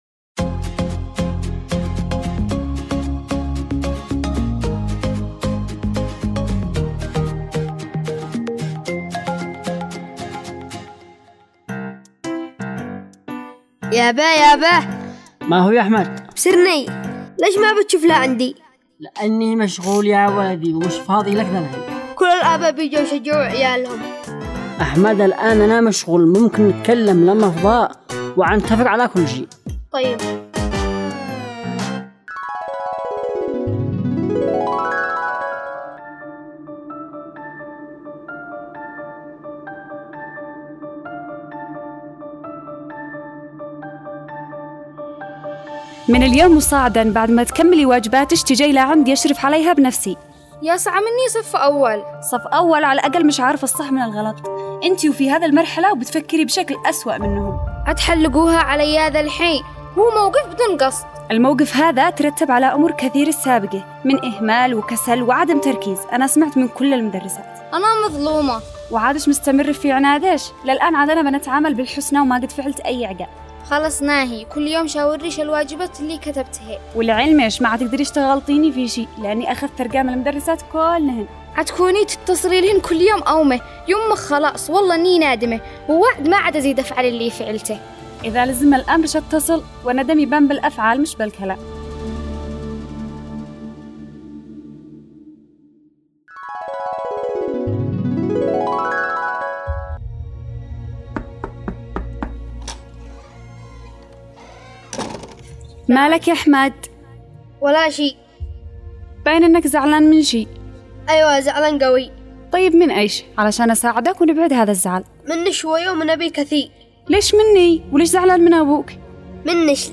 دراما رمضانية - عائلة مصطفى